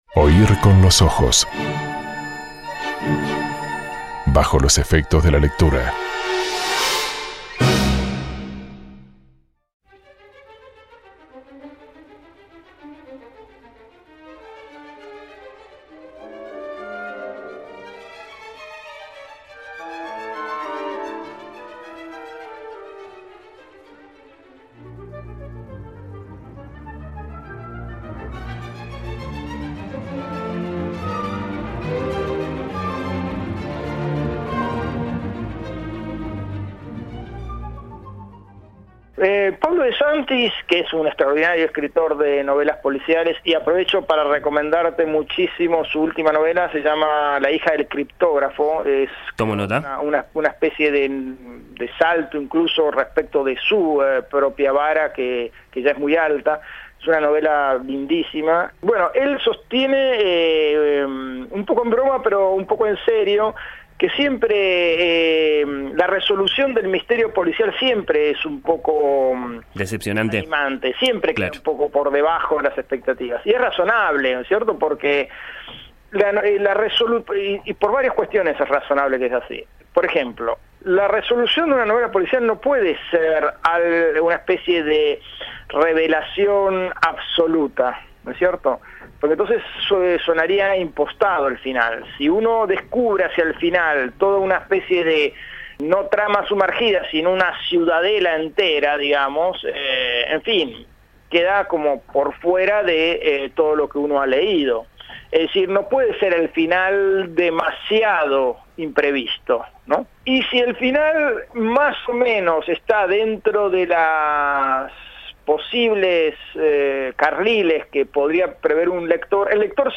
Oír con los ojos presentó a Pablo De Santis, en un diálogo